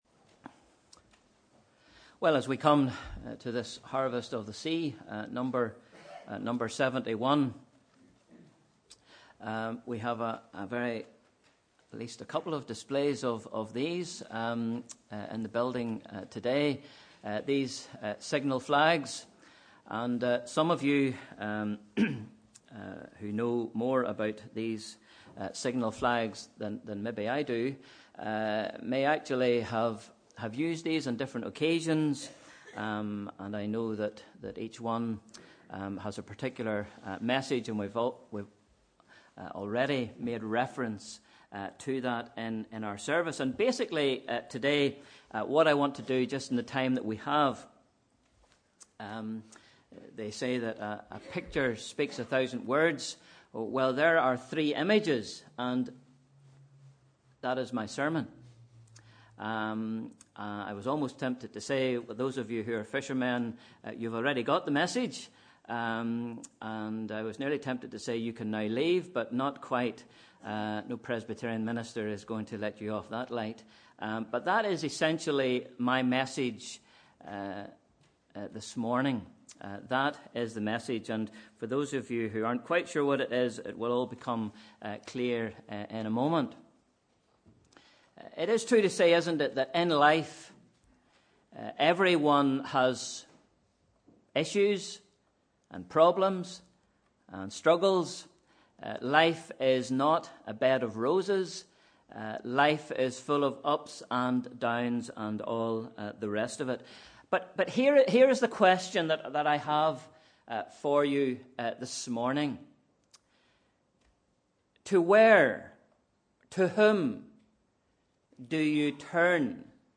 Sunday 4th December 2016 – Morning Service (Harvest of the Sea)